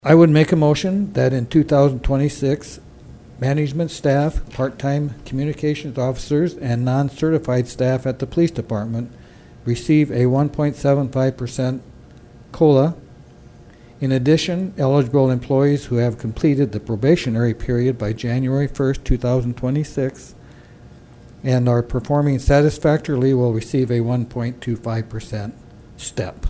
Mayor Steve Harding presented three changes to salary policy for 2026…